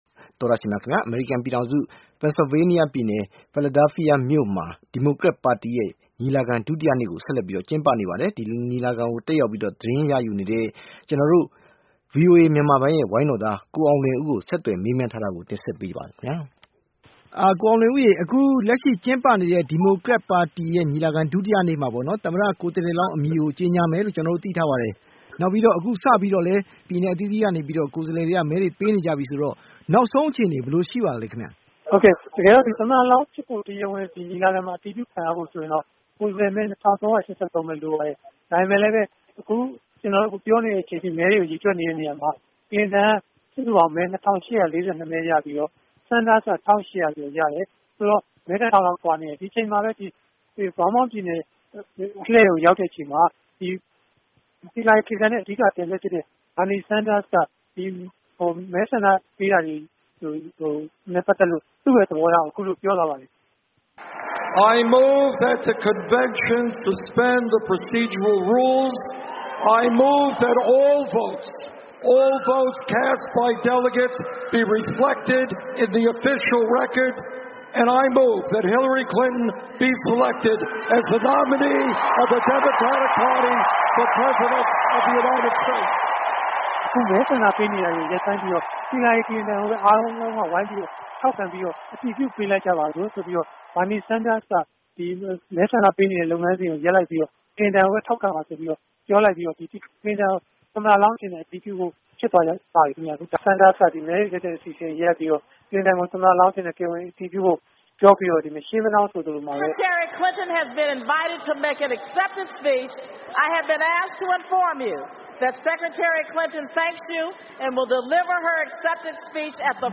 ဒီမိုကရက်ပါတီညီလာခံကနေ တိုက်ရိုက်သတင်းပေးပို့ချက်